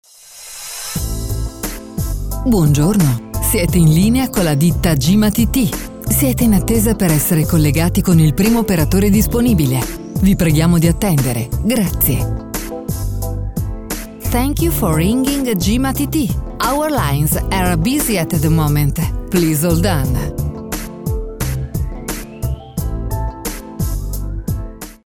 segreterie per risponditori telefonici